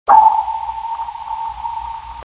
Denizaltı Gemisinde Sesler
DSH gemisi sonarı ping 5-kısa mesafe skalasında
ping5.mp3